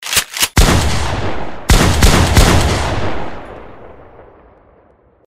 3shots
3shots.mp3